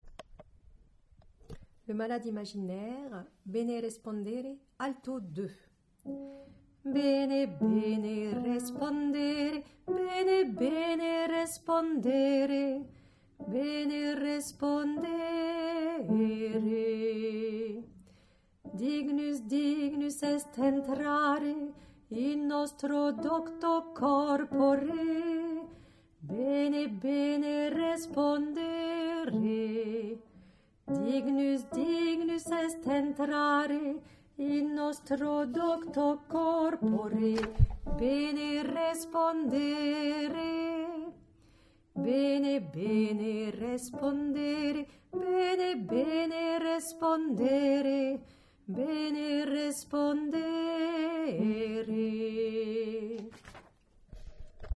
Alto2
mi_bene_Alto2.mp3